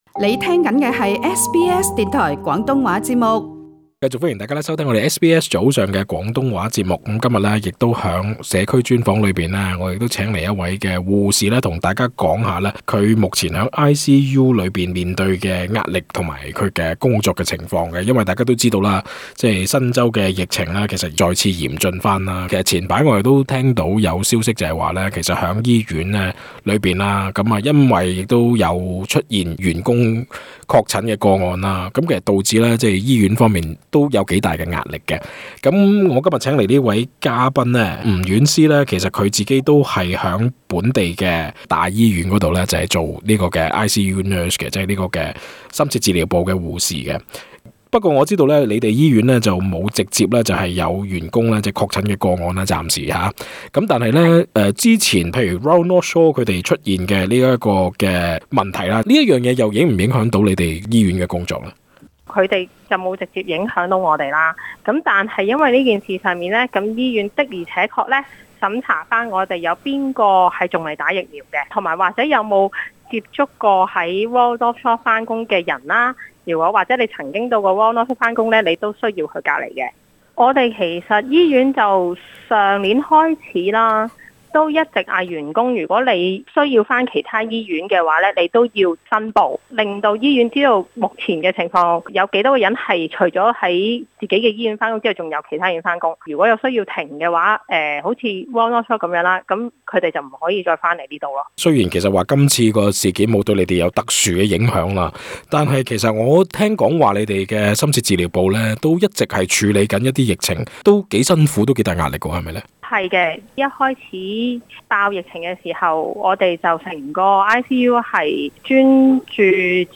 更多訪問內容請收聽足本錄音。